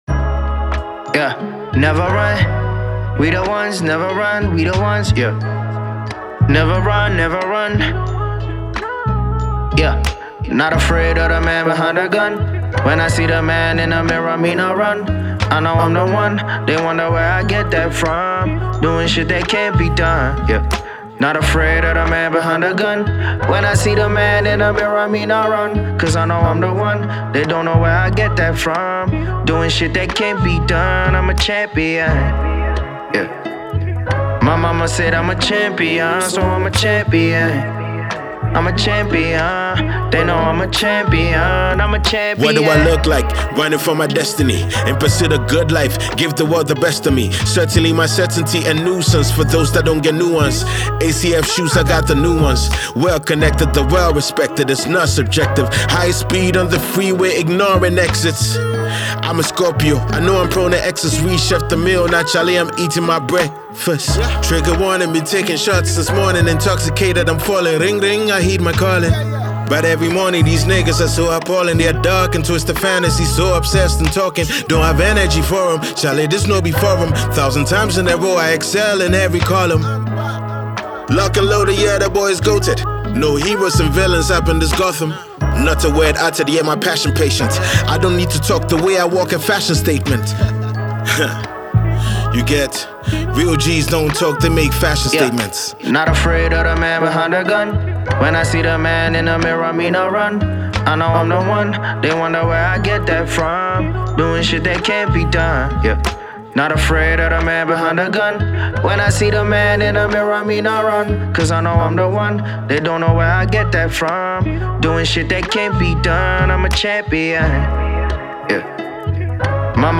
Hip-hop and Rap